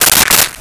Stapler 02
Stapler 02.wav